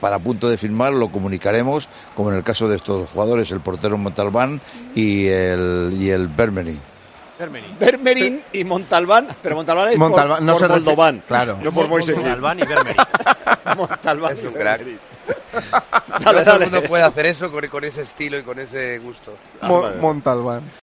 Cerezo 'resuelve' las dudas sobre la pronunciación del nombre de sus nuevos fichajes: "Montabán y Vérmerin"
El presidente del Atlético atendió a Dazn en la previa del encuentro ante el Valencia y ha llamado la atención la forma en la que ha llamado a los nuevos fichajes rojiblancos.
Preguntado sobre las nuevas incorporaciones, Cerezo dijo que se harán oficiales como lo hicieron con "Moldovan ('Montalbán') y Vermereen ('Vérmerin'"), cuando ya esté todo firmado.